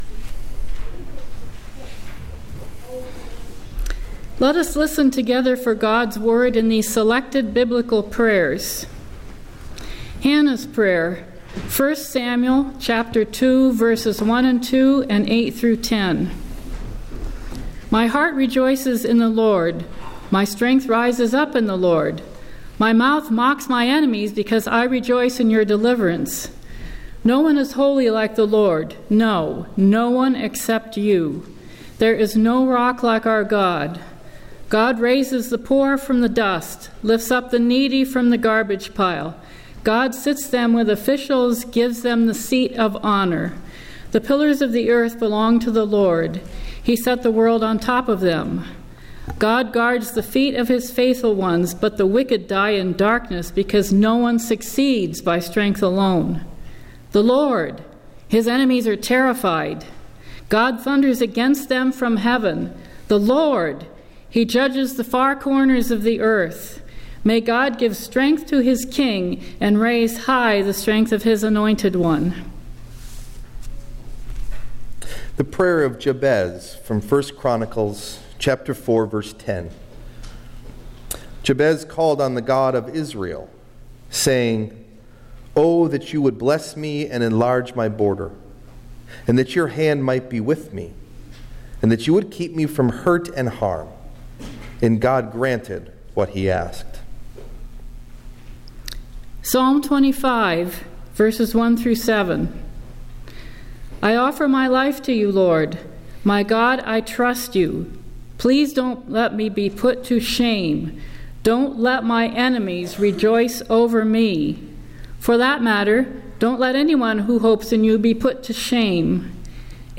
Message Delivered at: The United Church of Underhill (UCC and UMC)
Date: March 16th, 2014 , (Lent 2) Message Delivered at: The United Church of Underhill (UCC and UMC) Key Text(s): Selected readings on prayer This sermon is the second of a three part series on prayer. Today we ask together: how are followers of Jesus Christ to pray?